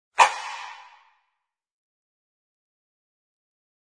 Descarga de Sonidos mp3 Gratis: interruptor 2.